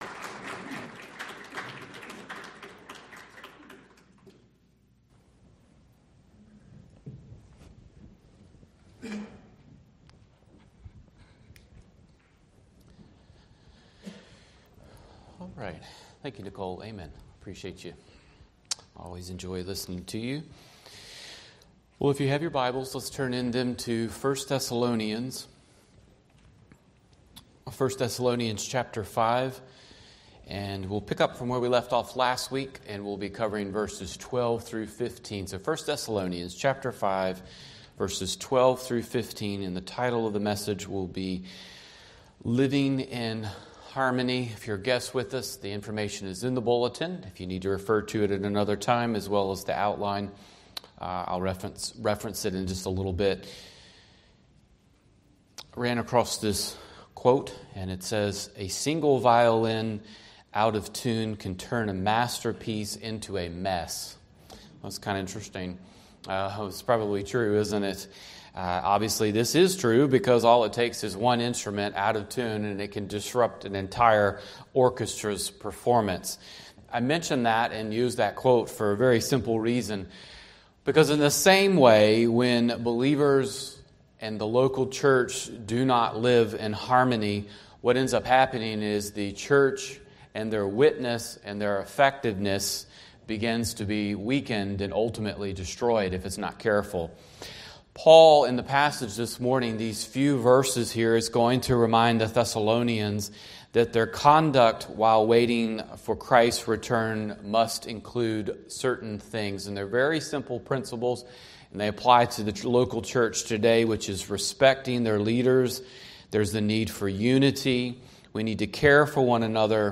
Sermons | Decatur Bible Church